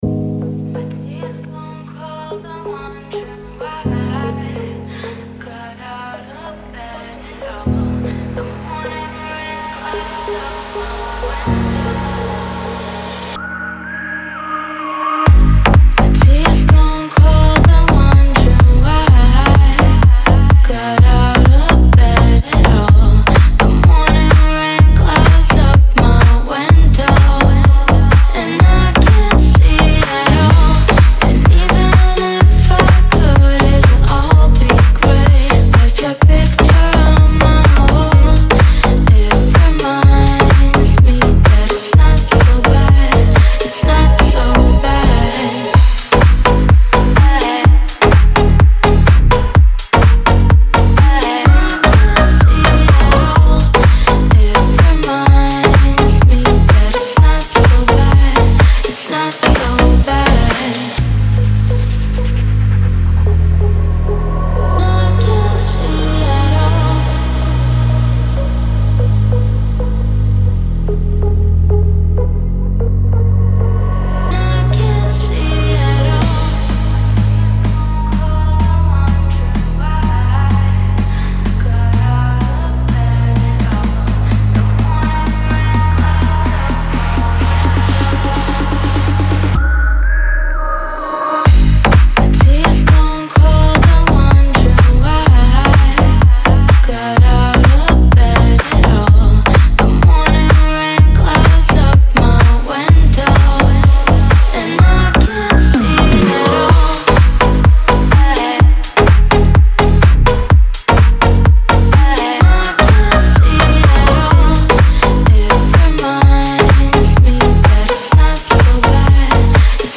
Стиль: Dance / Electronic / House / Pop